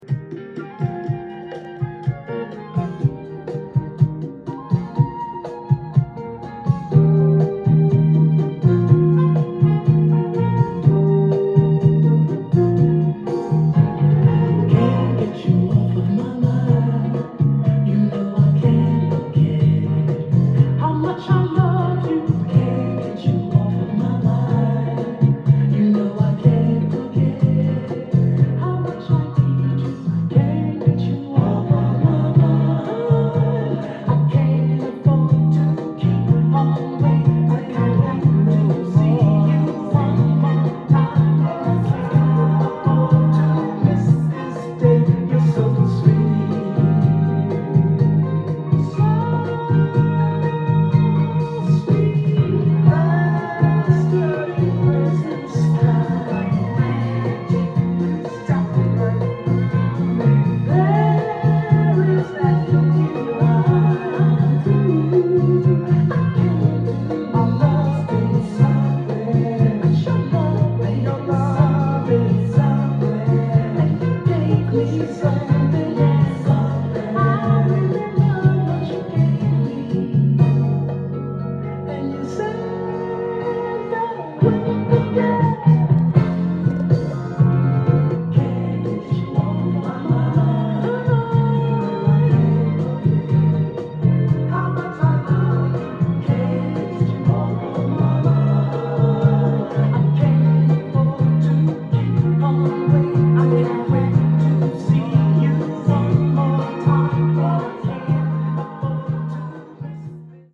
ジャンル：SOUL
店頭で録音した音源の為、多少の外部音や音質の悪さはございますが、サンプルとしてご視聴ください。
音が稀にチリ・プツ出る程度